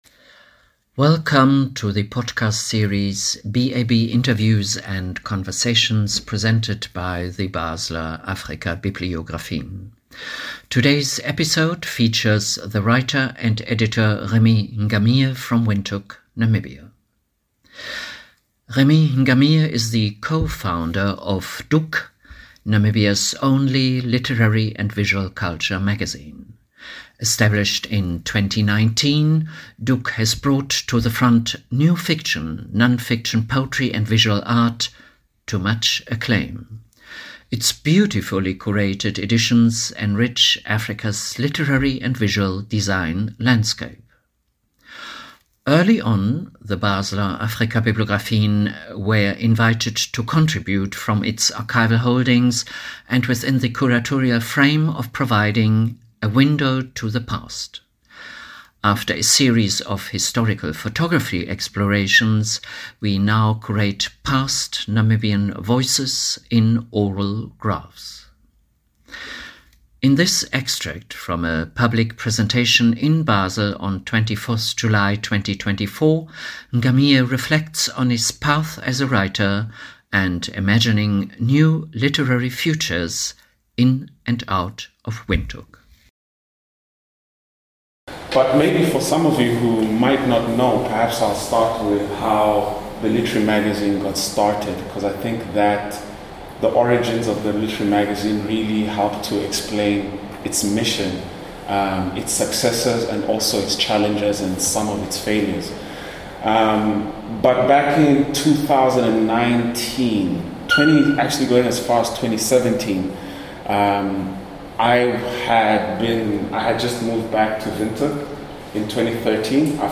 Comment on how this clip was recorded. Our podcast features extracts from past and recent interviews with scholars and BAB guests as well as selected recordings from the extensive southern African historical audio archive curated at the BAB.